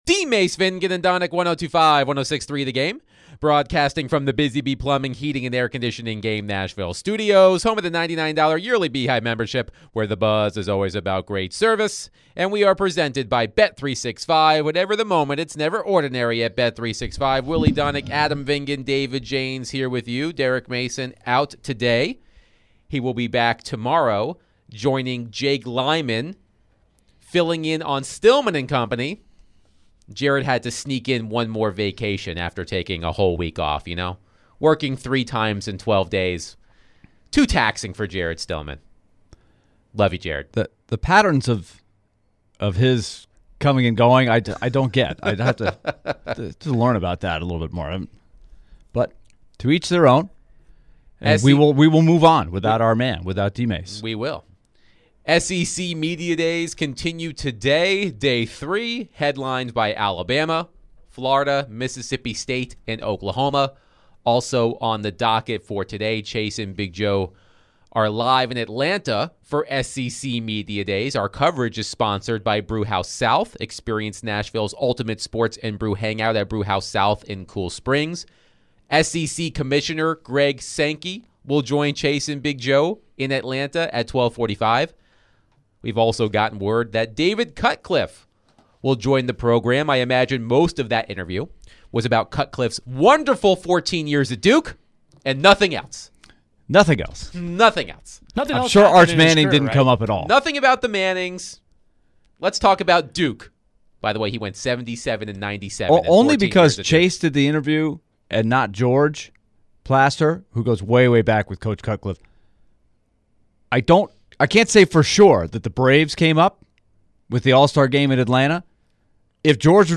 They were also joined by ESPN NFL Analyst Tim Hasselbeck to discuss NFL Training Camp, Titans, NFL, CFB and more. They also discuss Who is the most important non-QB for the Titans.